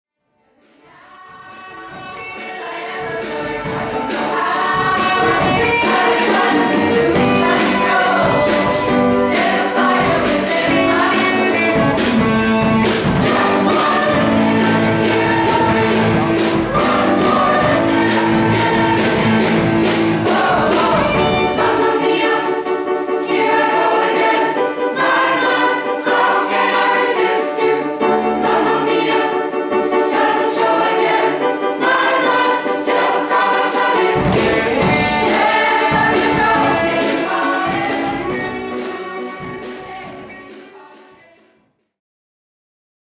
Chorkonzert
Januar trat der Freifach-Chor mit acht Songs auf die Bühne, welche unter dem Titel «Together» die Liebe von verschiedenen Seiten präsentieren. Zuerst dramatisch und düster, schliesslich leicht und unbeschwert, bis zum versöhnlichen Happy End.